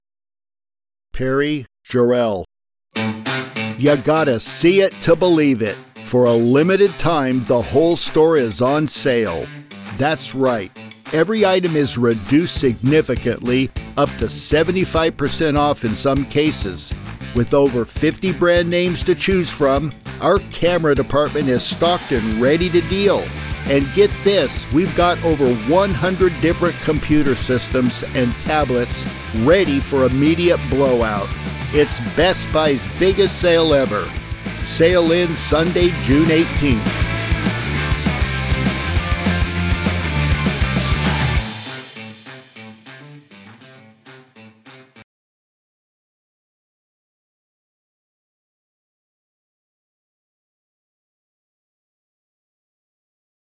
(Old man, farmer, hillbilly, dopey bird)
Middle Aged
Senior